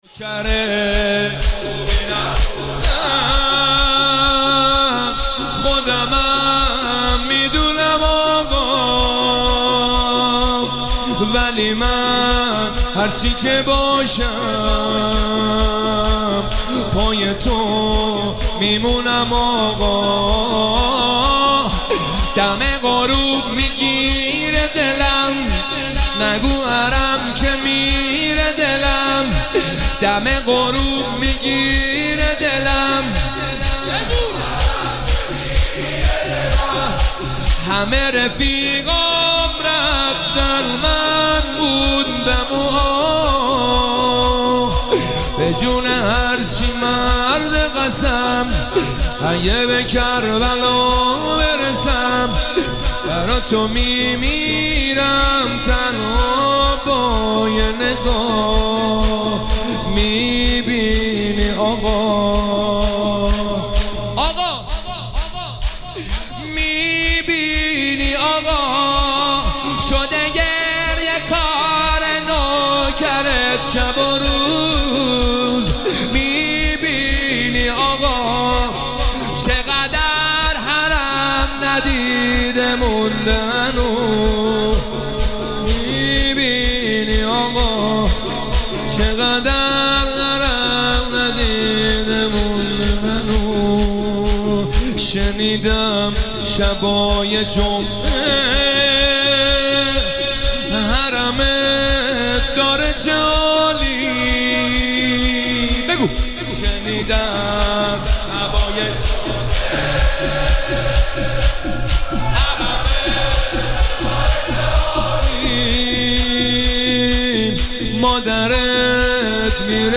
محفل عزاداری شب هشتم محرم